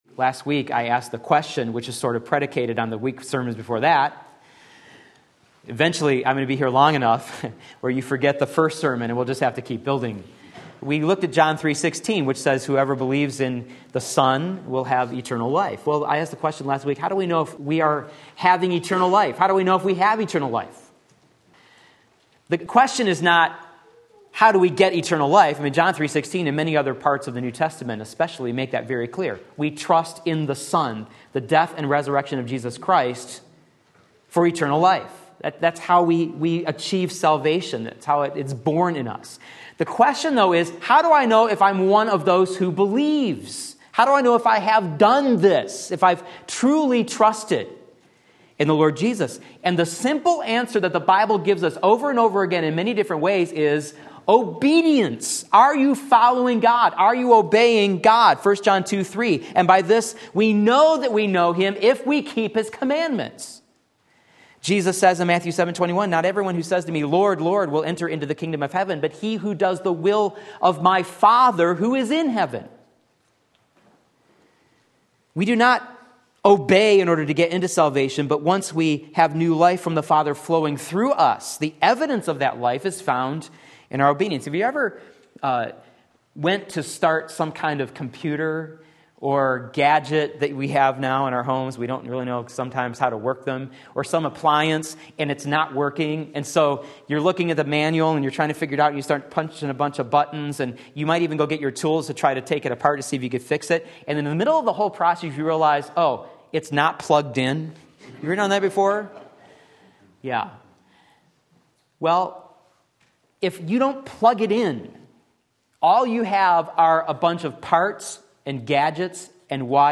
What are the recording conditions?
Part 2a Genesis 3 Sunday Morning Service